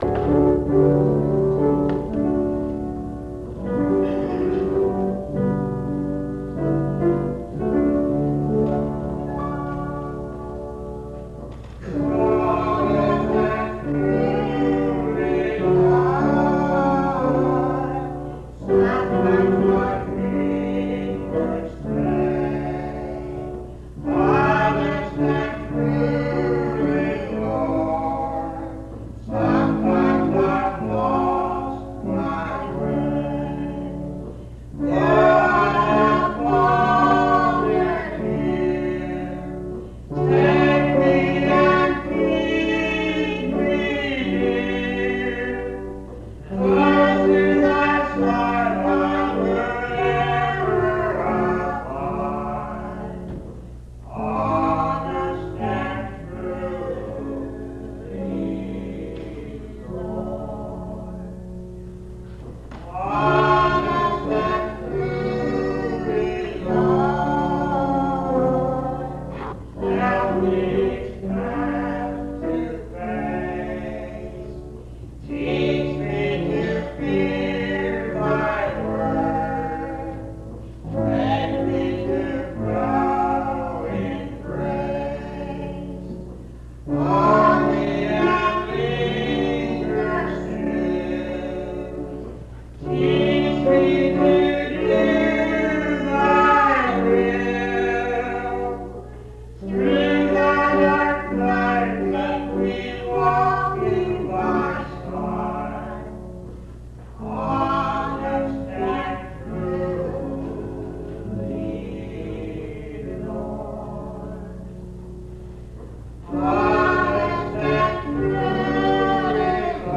This recording is from the Monongalia Tri-District Sing. Church of the Bretheren, Morgantown, Monongalia County, WV, track 138E.